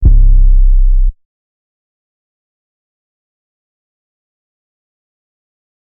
MexikoDro 808.wav